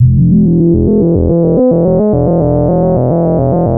JUP 8 E2 11.wav